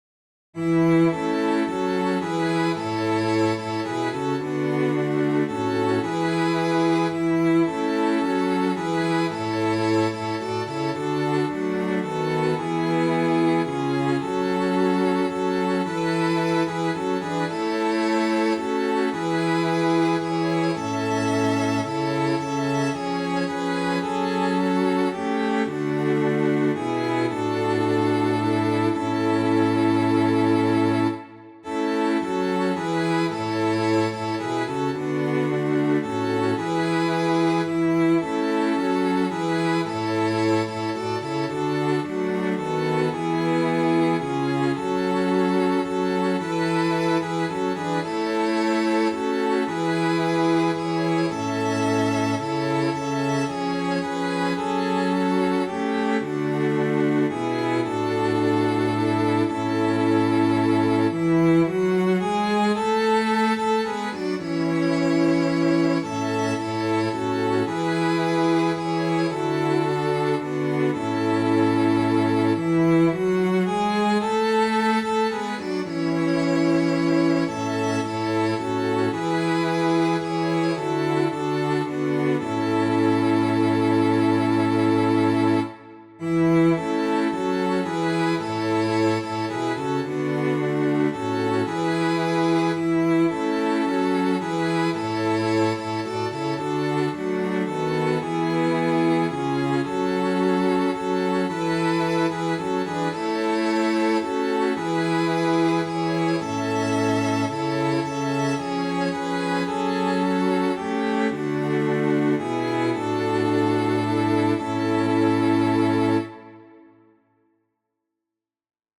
Chants de Communion Téléchargé par